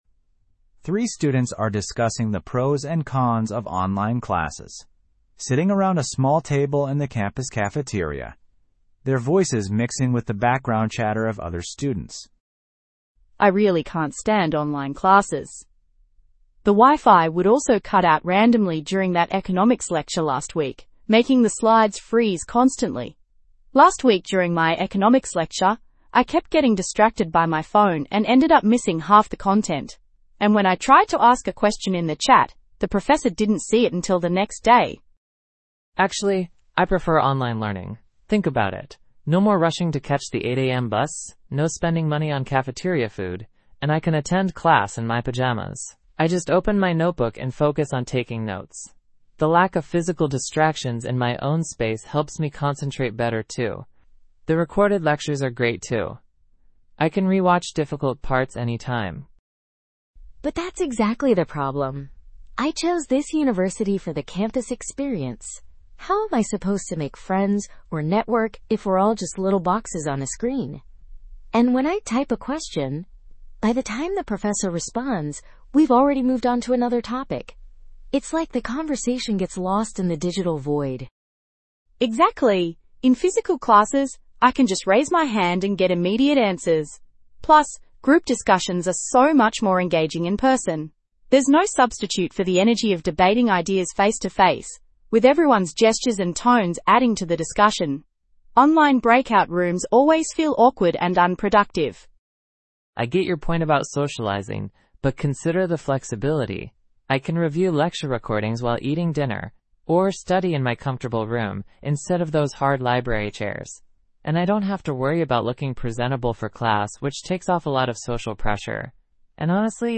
PTE Summarize Group Discussion – Meeting